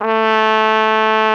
Index of /90_sSampleCDs/Roland L-CD702/VOL-2/BRS_Flugelhorn/BRS_Flugelhorn 1